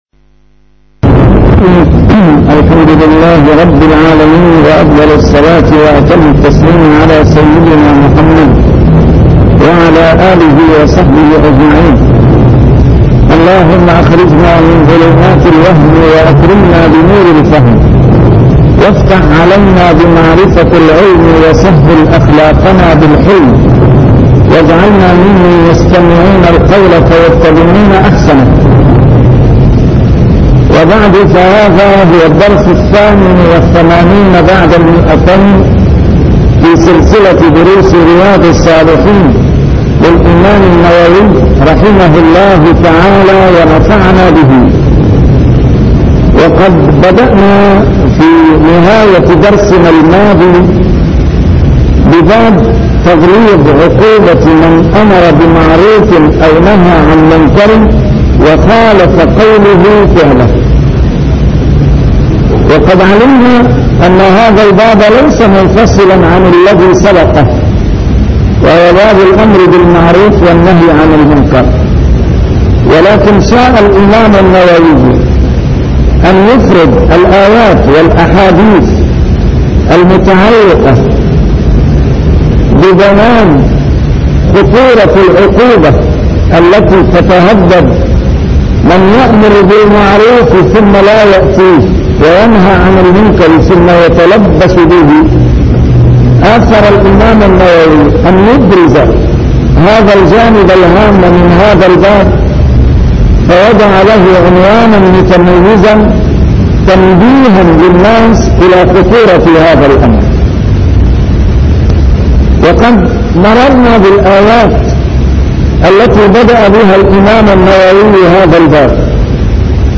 A MARTYR SCHOLAR: IMAM MUHAMMAD SAEED RAMADAN AL-BOUTI - الدروس العلمية - شرح كتاب رياض الصالحين - 288- شرح رياض الصالحين : تغليظ عقوبة من خالف الأمر بأداء الأمانة